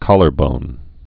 (kŏlər-bōn)